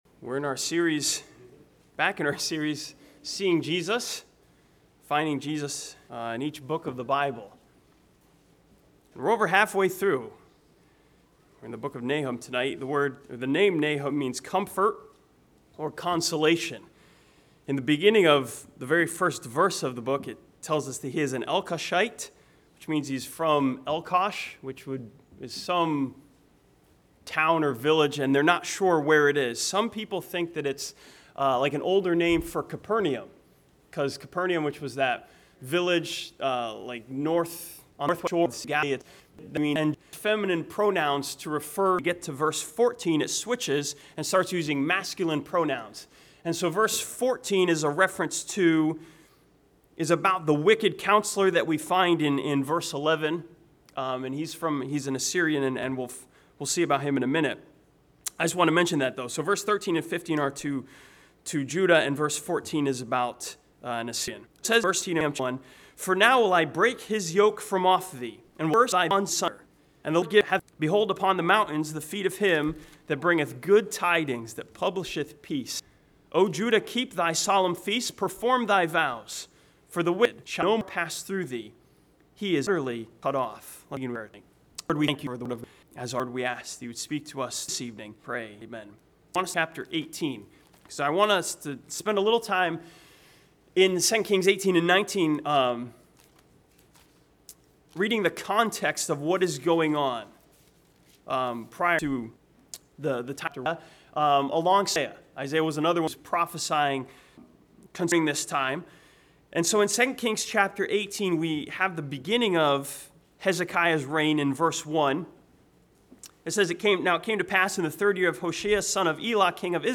This sermon from Nahum chapter one sees Jesus as the messenger who comes bearing good tidings of peace.